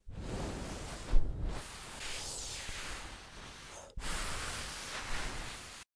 麦克风
描述：有些人在吹麦克风来模仿风。
标签： 海洋 微风 大风
声道立体声